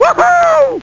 Amiga 8-bit Sampled Voice
nextlevel_snd.mp3